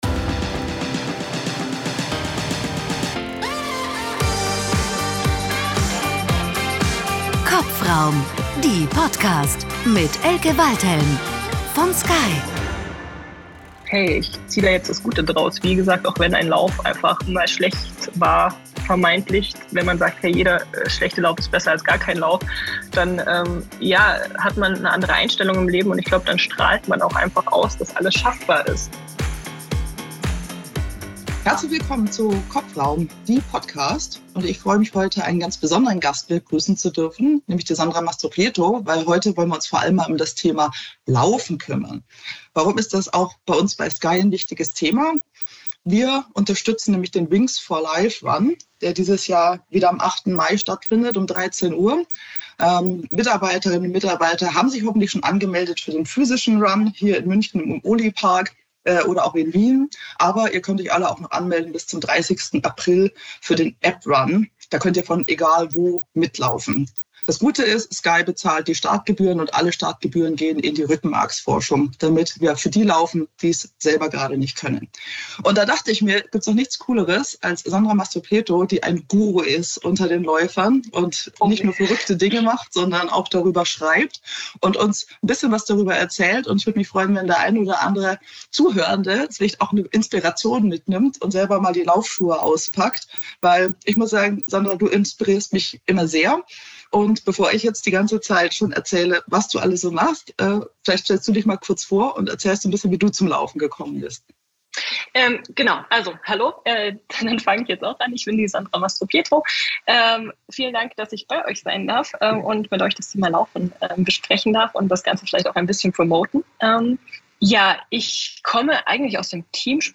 Was man in dieser Zeit alles so macht und warum laufen gar nicht langweilig ist, darüber redet die Lauftrainerin im Interview mit mir.